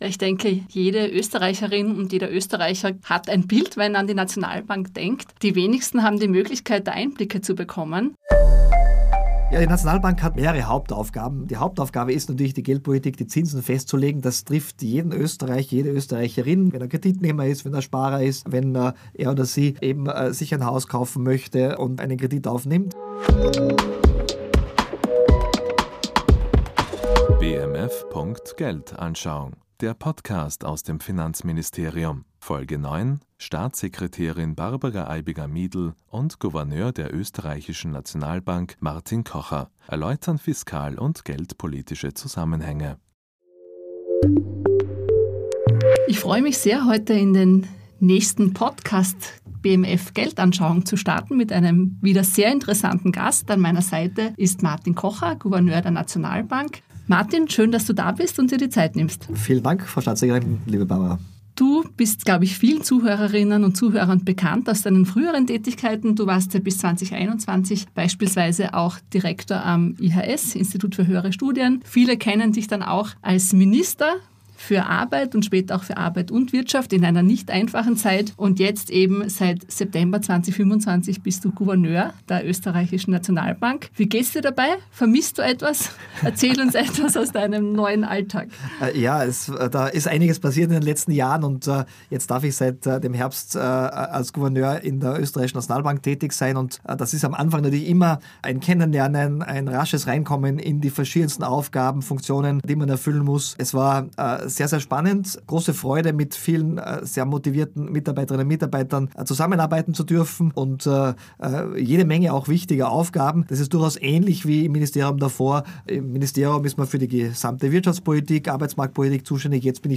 Gouverneur der Oesterreichischen Nationalbank (OeNB) Martin Kocher und Finanzstaatssekretärin Barbara Eibinger-Miedl sprechen über die Tätigkeit der OeNB, die Rolle der EZB und die Finanzbildung.